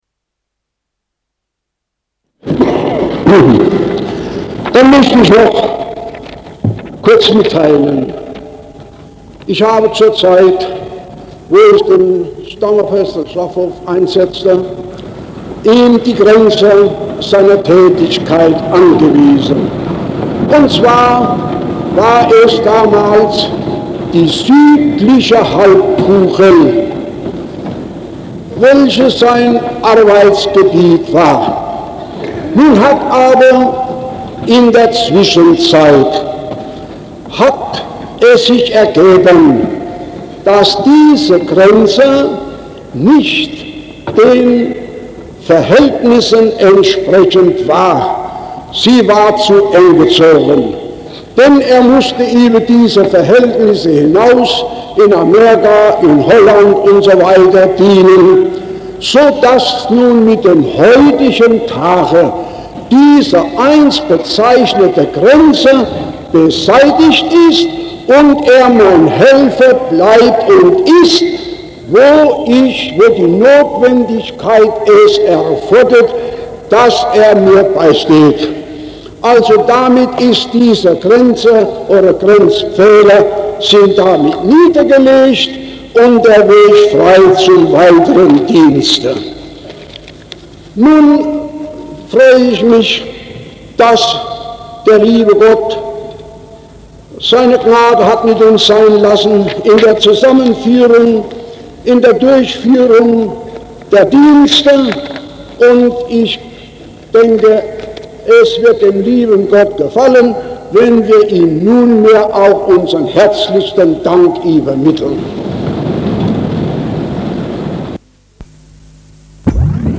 5. August 1951 Neuapostolisches Bundesfest in Frankfurt (Festgottesdienst)
Am Gottesdienst selbst nahmen in dieser Halle über 14.000 teil.
Das Eingangslied erklang, ausgeführt von einem Musikchor, und der Stammapostel betrat den Altar.